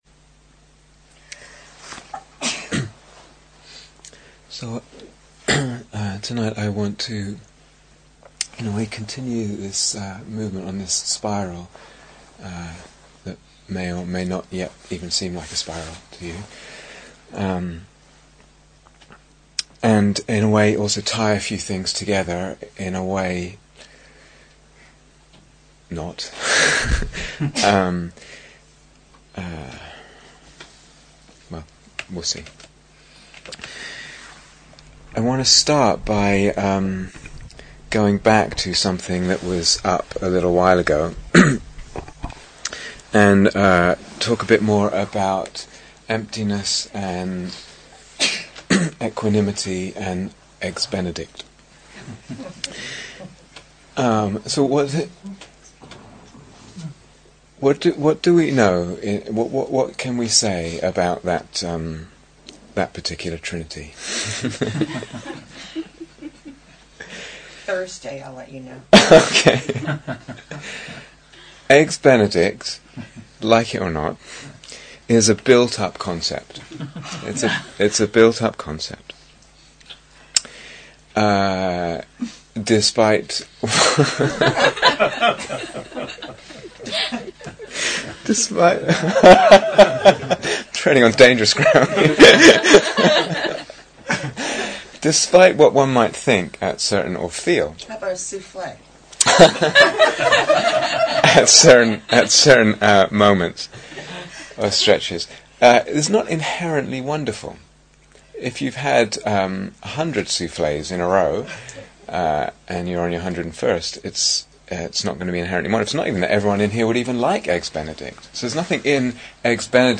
Here is the full retreat on Dharma Seed Please note that these talks are from a 4 week retreat for experienced meditators.